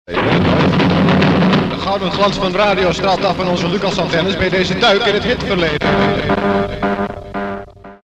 Met op het einde zo’n typische jaren ’60 echo.